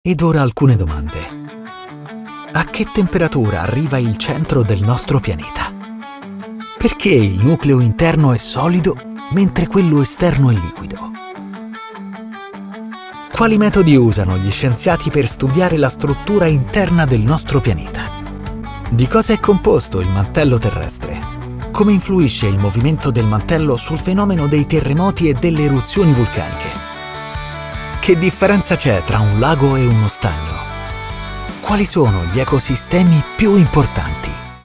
Commercieel, Zacht, Zakelijk, Speels, Veelzijdig
Explainer